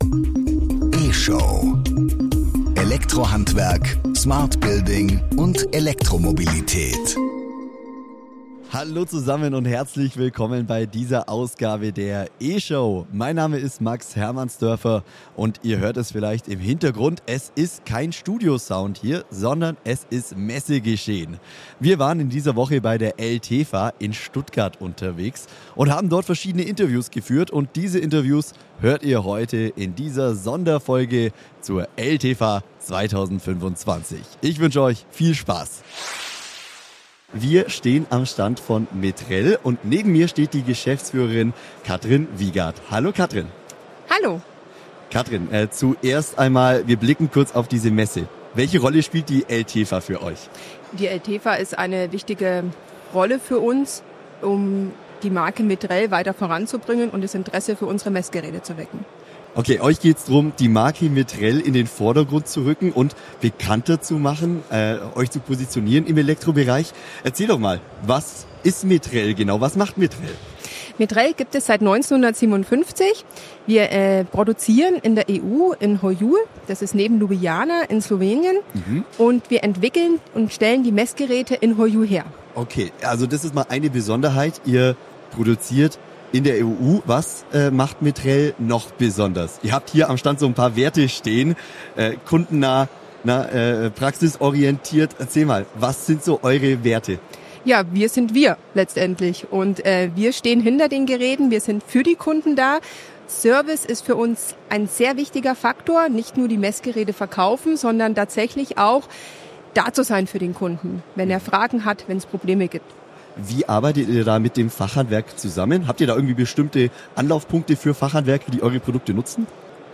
Mit dem mobilen Podcaststudio war das Handwerker Radio Team in dieser Woche bei der eltefa in Stuttgart unterwegs.